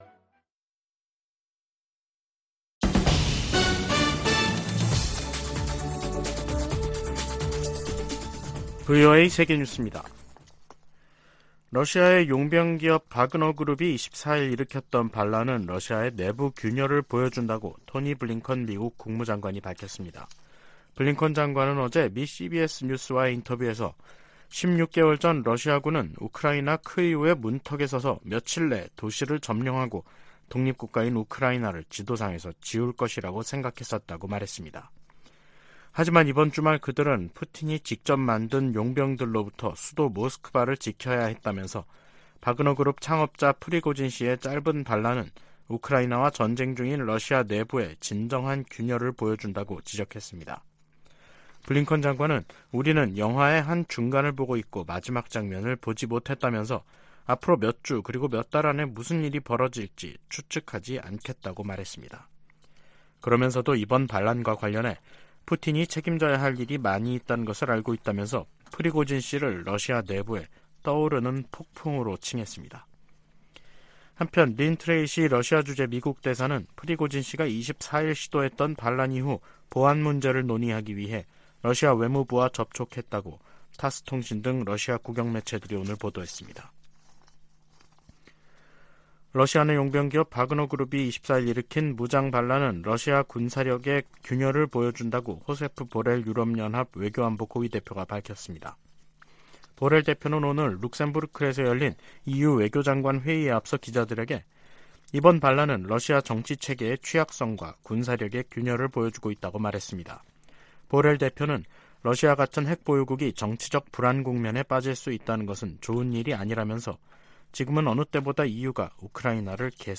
VOA 한국어 간판 뉴스 프로그램 '뉴스 투데이', 2023년 6월 26일 3부 방송입니다. 북한 동창리 서해위성발사장의 새 로켓 발사대 주변에서 새로운 움직임이 포착돼 새 발사와의 연관성이 주목됩니다. 미국은 중국에 대북 영향력을 행사할 것을 지속적으로 촉구하고 있다고 백악관이 밝혔습니다. 국무부는 북한 식량난이 제재 때문이라는 러시아 대사의 주장에 대해 북한 정권의 책임을 다른 곳으로 돌리려는 시도라고 비판했습니다.